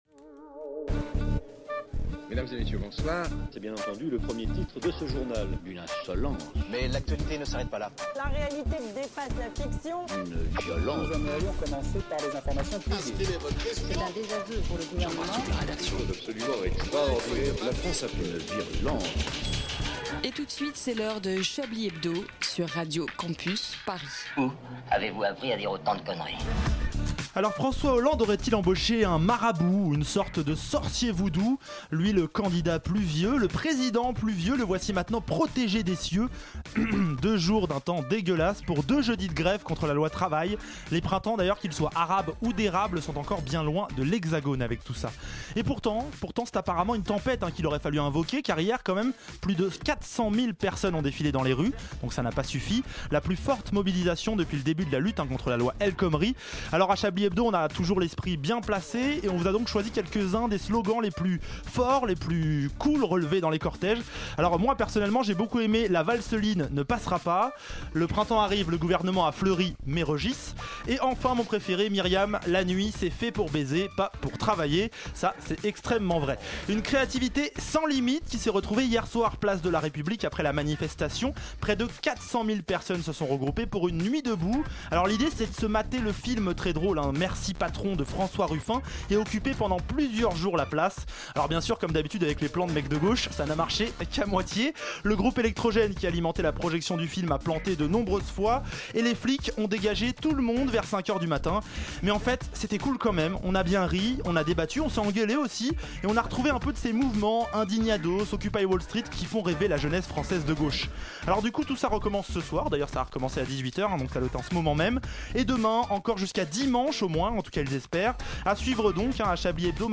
Cette semaine la conférence de rédaction de votre hebdomadaire préféré tombe un 1er Avril.